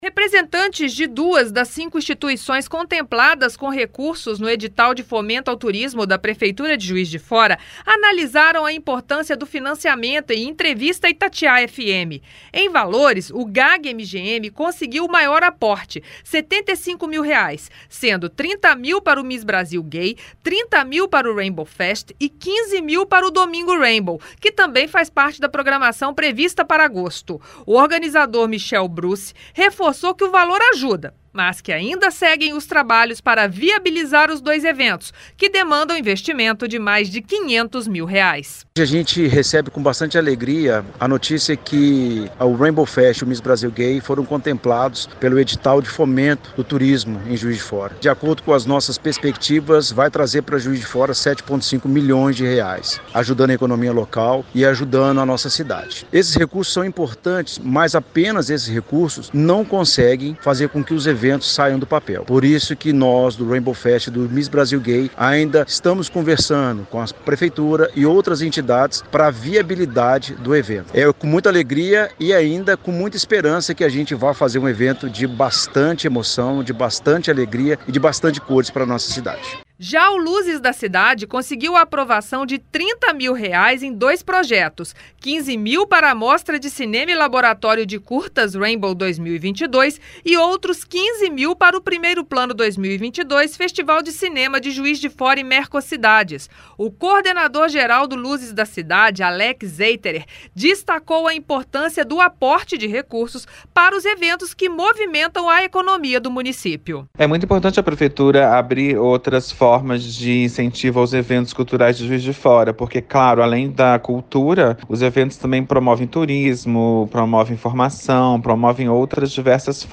Após a Secretaria Municipal de Turismo divulgar as instituições beneficiadas no edital de fomento, a reportagem da Itatiaia FM ouviu representantes de duas entidades selecionadas.